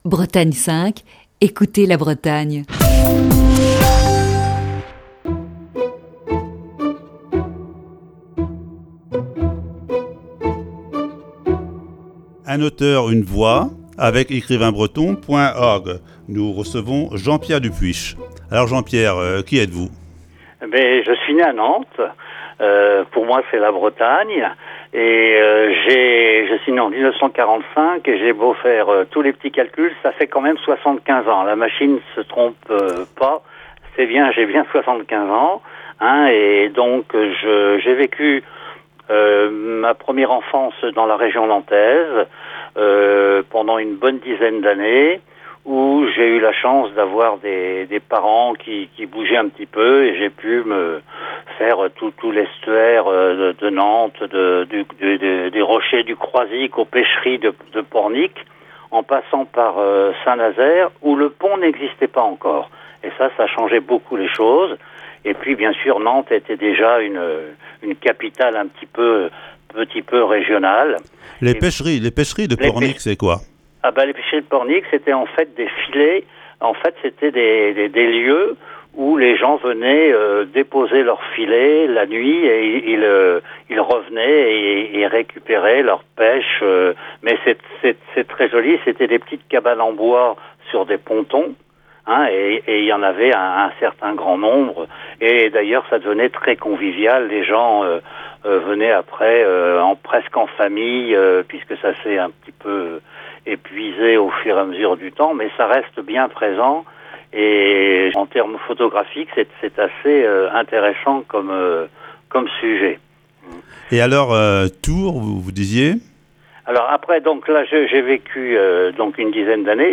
Voici, ce lundi, la première partie de cet entretien.